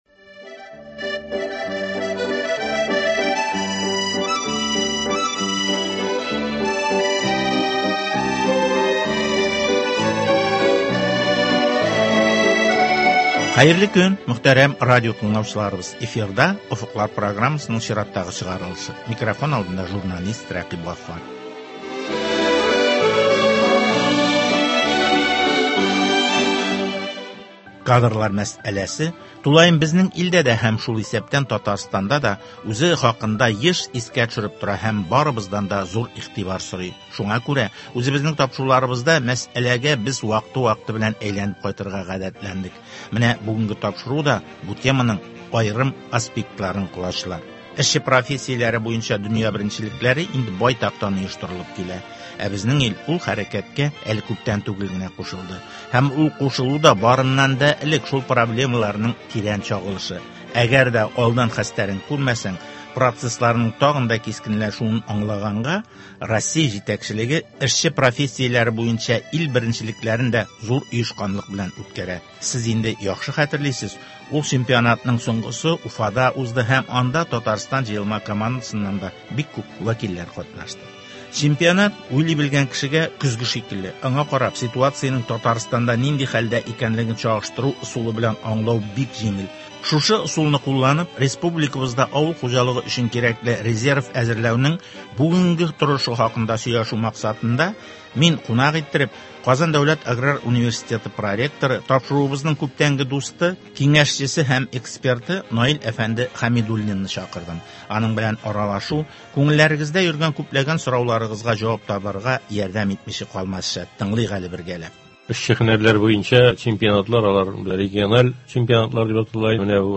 сөйләшү